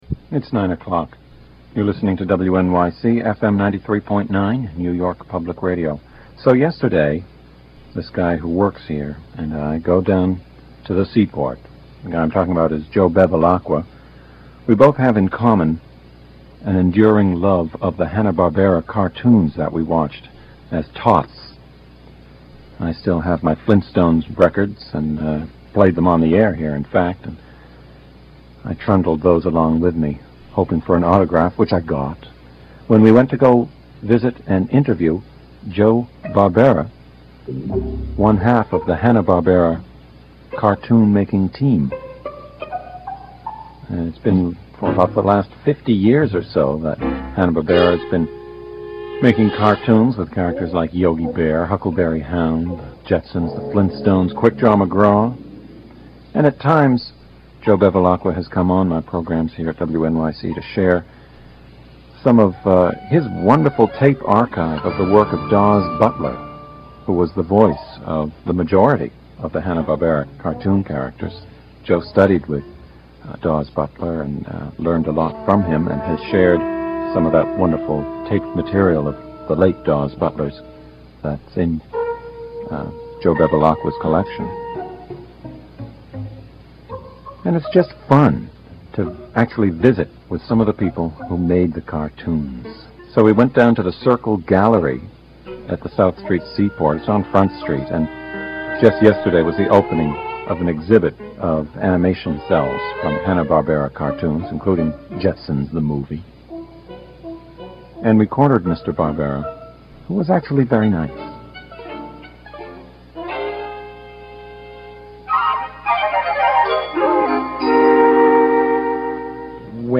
Interview mit Joseph Barbera